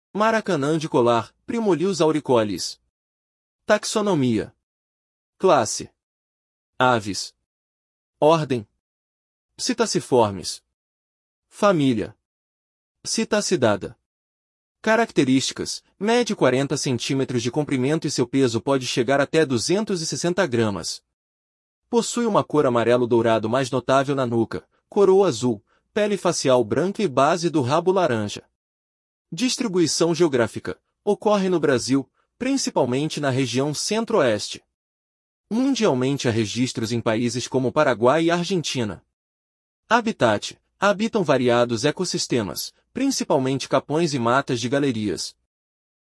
Maracanã-de-colar (Primolius auricollis)